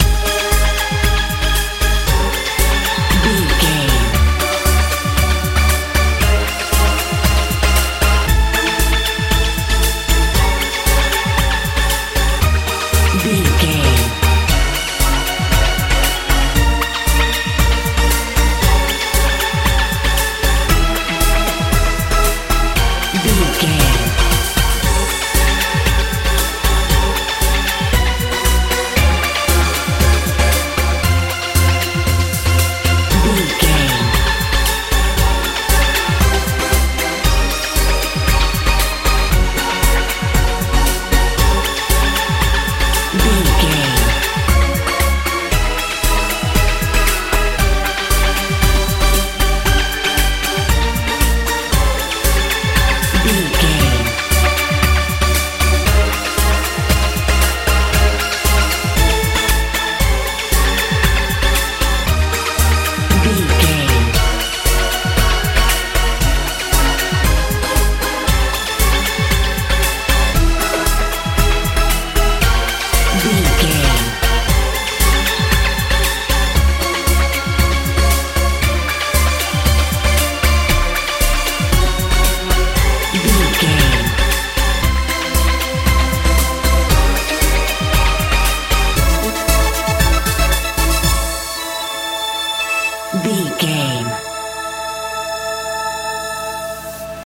modern pop feel
Ionian/Major
A♭
mystical
futuristic
bass guitar
synthesiser
drums
90s